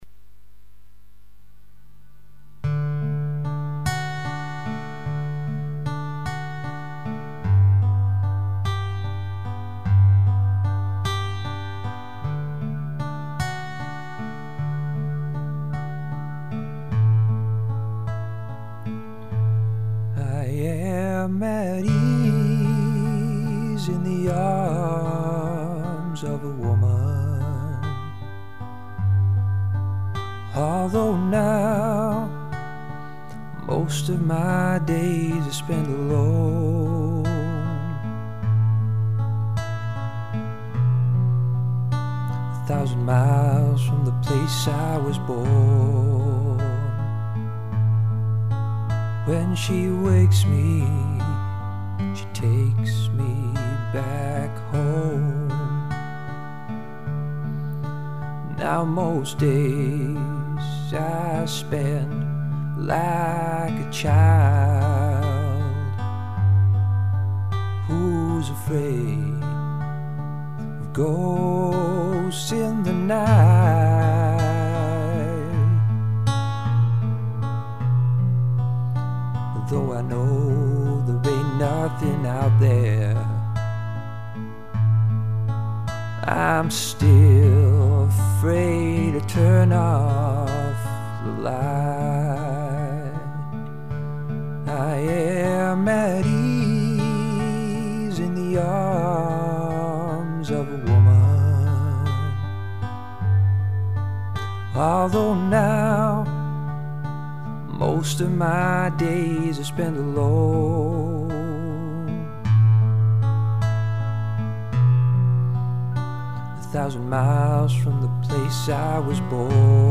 Heel mooi gezongen! Warm geluid.
ik begeleid mezelf ook ja....dwz: alles uit een keyboardje